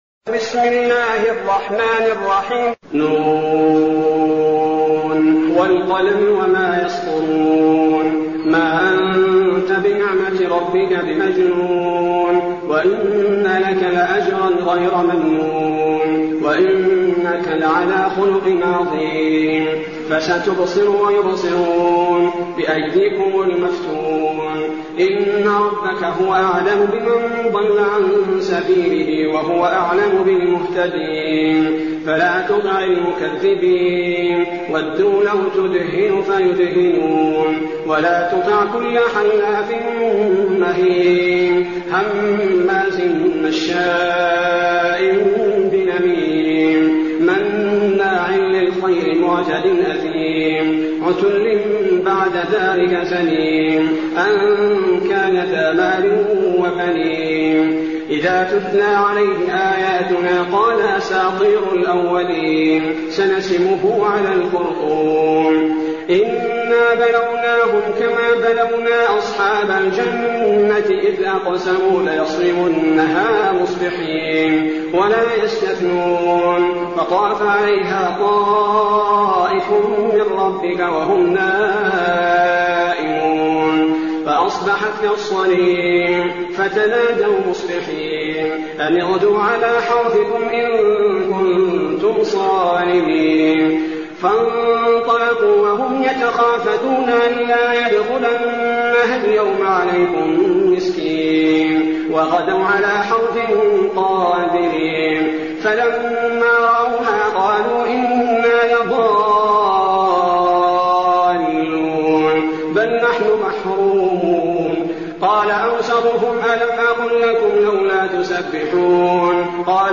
المكان: المسجد النبوي الشيخ: فضيلة الشيخ عبدالباري الثبيتي فضيلة الشيخ عبدالباري الثبيتي القلم